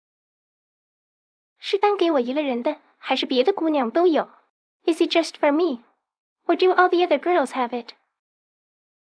GPT-SoVITS模型|《红楼梦》林黛玉声音配音模型
1. 温婉细腻：林黛玉作为一位才情出众、性格敏感多愁的女性角色，其声音很可能带有一种温婉细腻的感觉，与她温柔多情的性格相契合。
3. 清晰悦耳：作为一位受过良好教育的大家闺秀，林黛玉的发音应该非常清晰，语调抑扬顿挫，给人以悦耳之感。
GPT-SoVITS模型配音效果